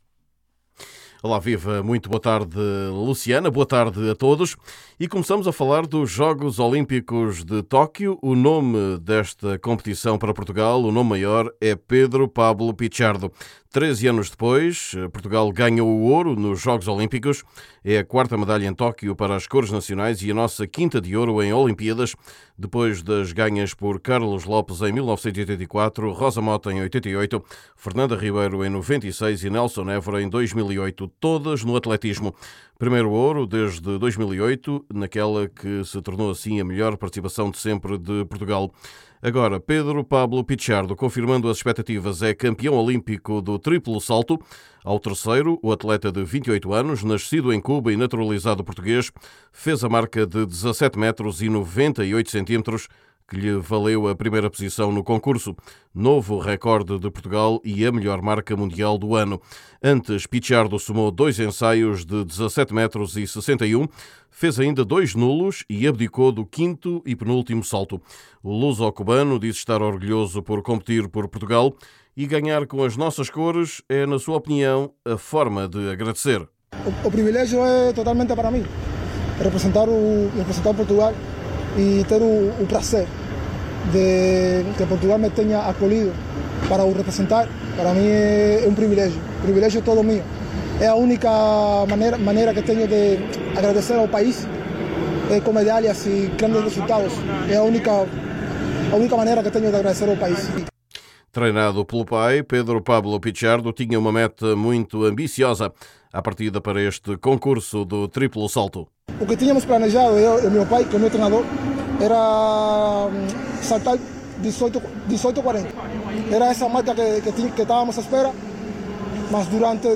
Pedro Pablo Pichardo é o novo herói lusitano, depois de ganhar o concurso do triplo salto em Tóquio. Confira a entrevista com o luso-cubano.